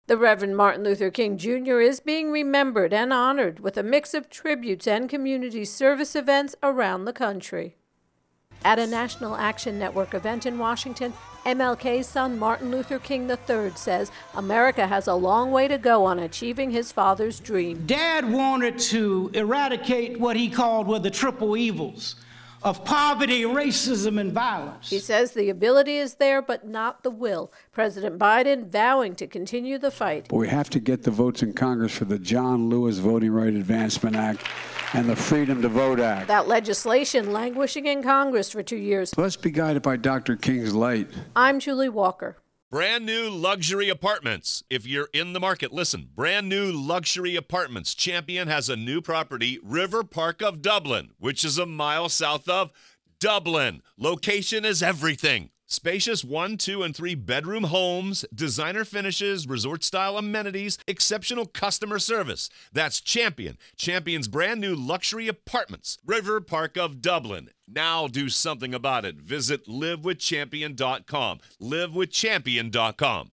reports on MLK Racial Justice